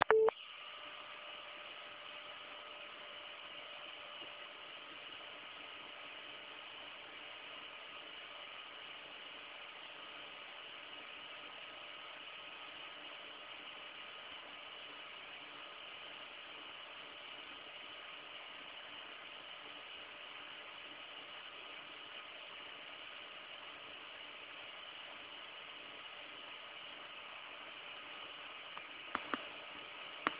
Chłodzenie karty jest standardowe i do cichych nie należy.
Tutaj 30 sekundowe nagranie z pracy karty.